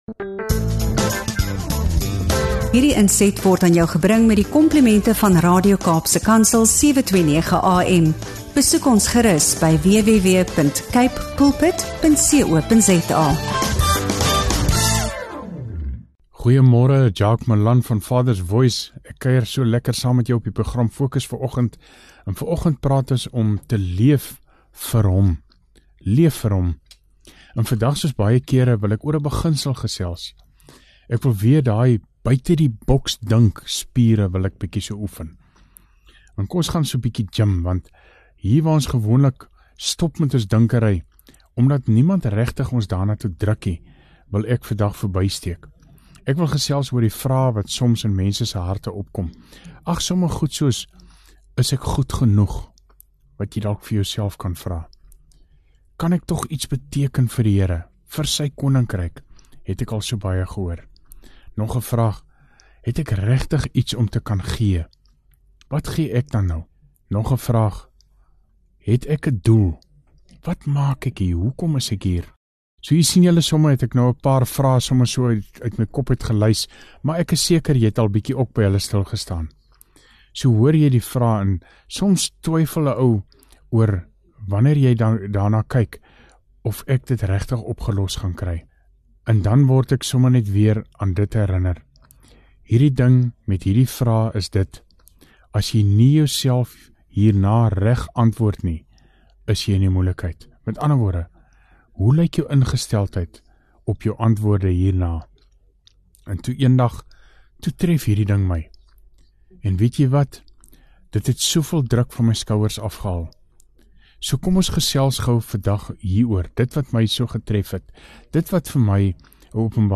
FOCUS & FOKUS DEVOTIONALS